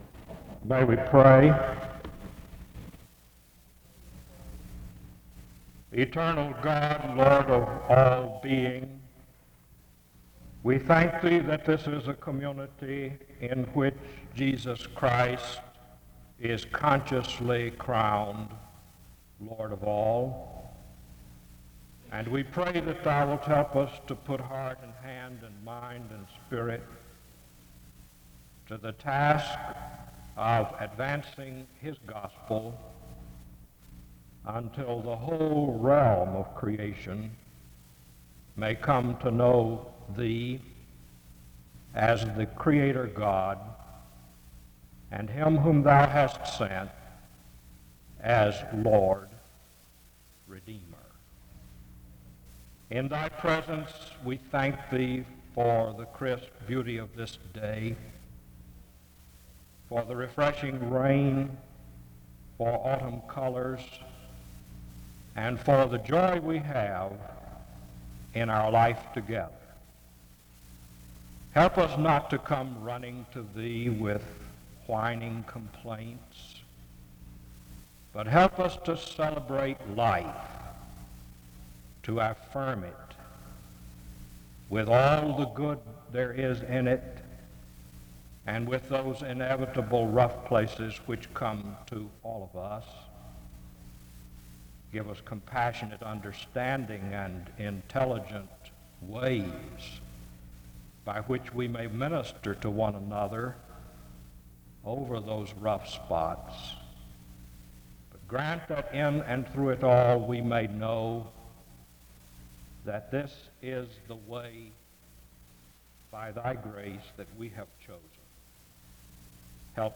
The service starts with a word of prayer from 0:00-3:16. Special music plays from 3:20-4:02.